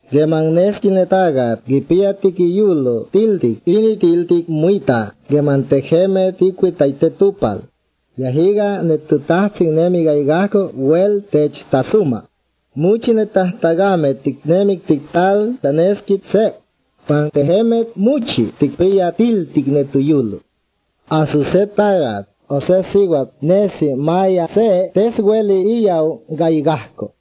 (I hear no breathy or creaky voicing, and the variations in pitch that I’m hearing strike me as typical for a stress language, not a tone or pitch accent language, but that’s not a super informed guess.)
The recording comes from the GRN .